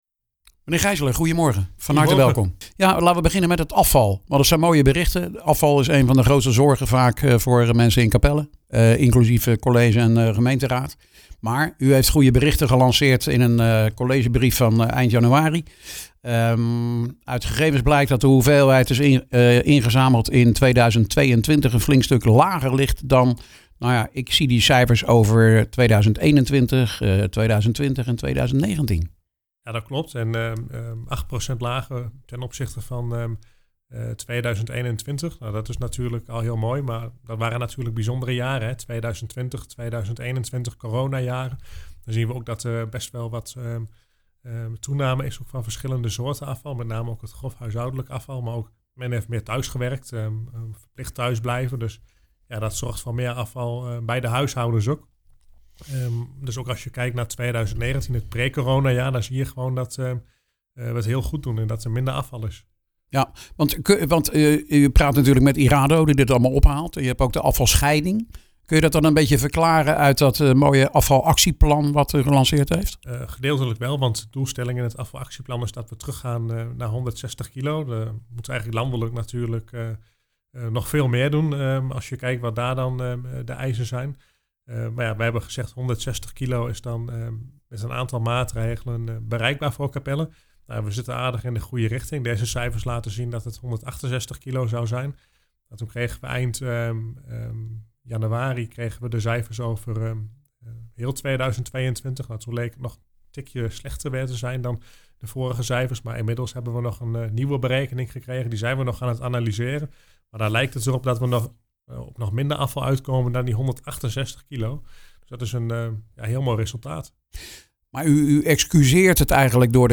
praat erover met wethouder Sjoerd Geissler